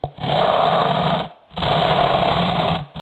Giant Cartoon Snoring Sound Effect Free Download
Giant Cartoon Snoring